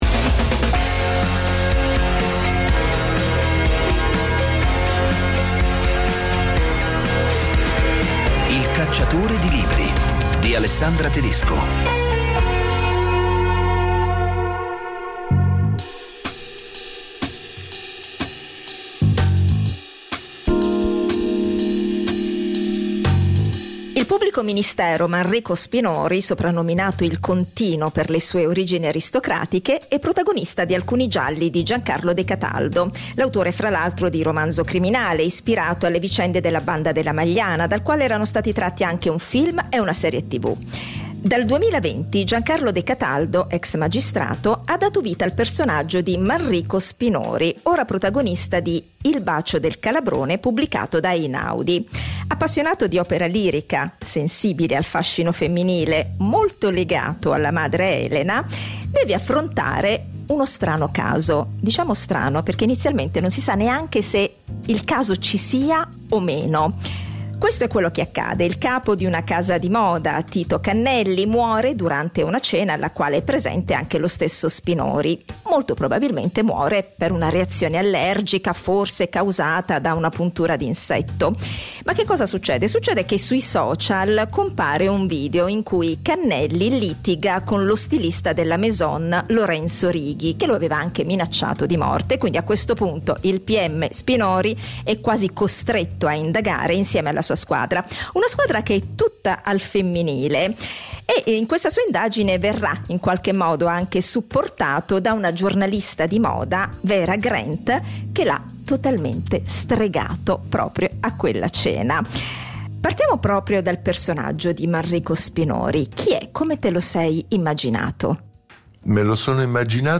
Un’inviata virtuale in libreria per scoprire le ultime novità del mercato editoriale. In ogni puntata due interviste dal vivo a scrittrici e scrittori, italiani e stranieri, per parlare dei romanzi: dai personaggi ai temi, dalle trame ai retroscena della scrittura.